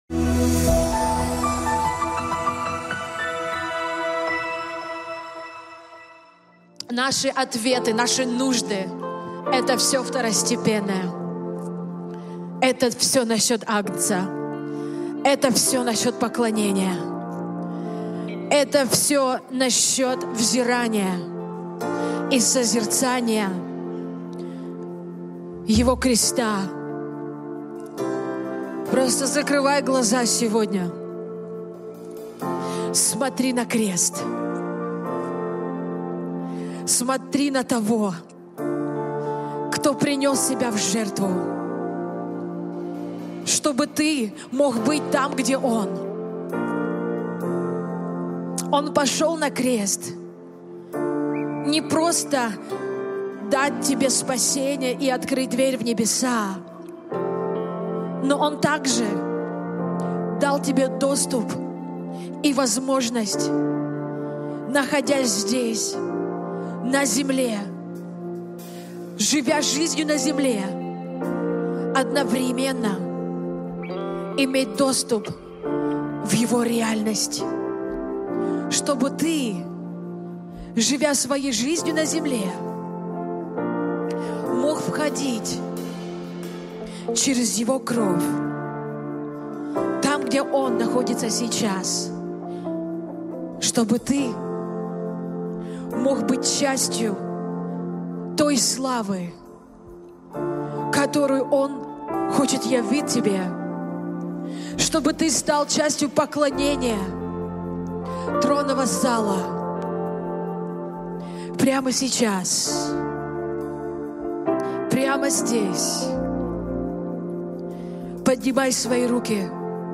Transformation Center Молитва 839
07:59 Transformation Center Молитва 839 МОЛИТВА_839.mp3 Категория : Молитвы Центра